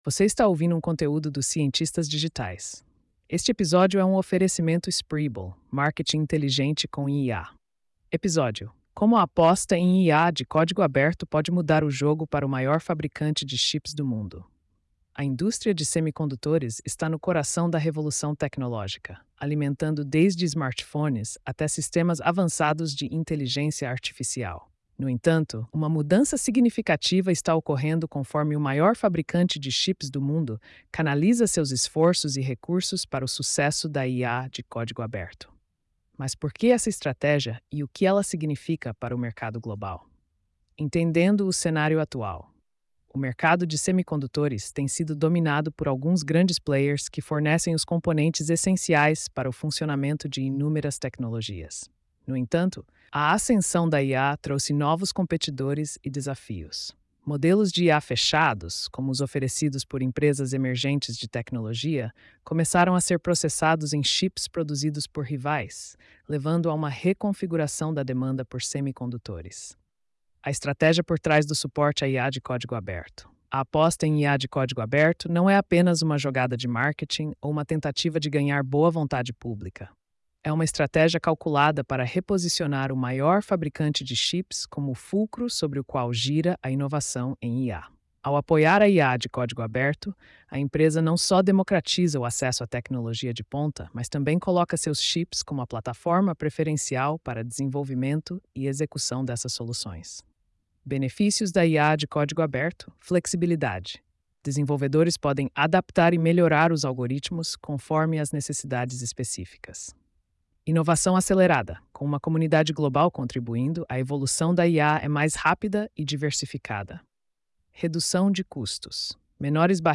post-4606-tts.mp3